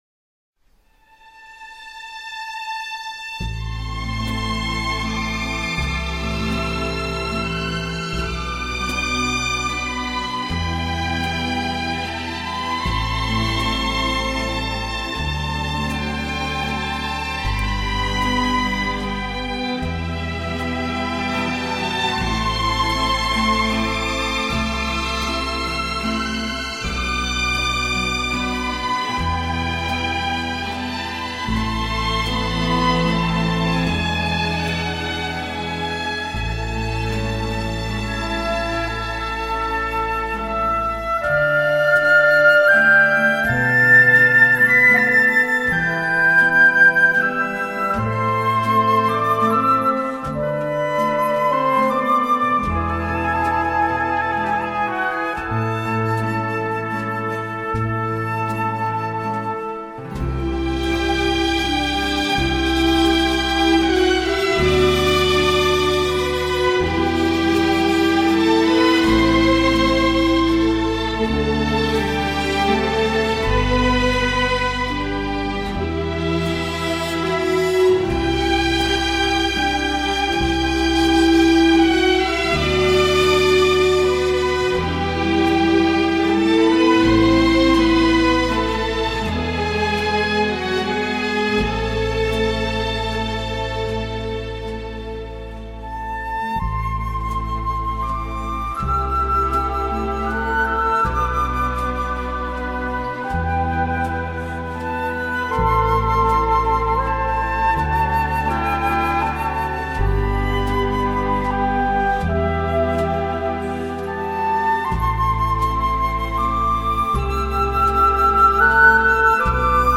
这个乐团的演奏 风格流畅舒展，旋律优美、动听，音响华丽丰满。
这种富 有特色的弦乐演奏，就此成为他的乐团所独有的音乐特色。